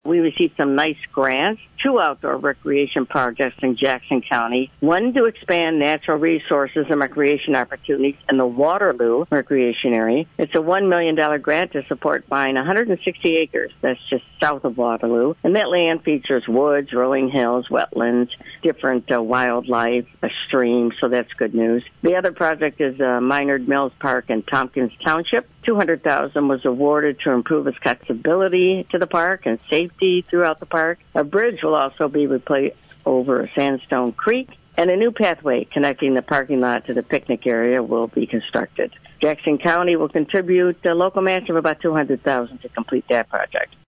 This full interview is available to listen to and download on this website, and State Representative Kathy Schmaltz regularly joins A.M. Jackson on Friday mornings.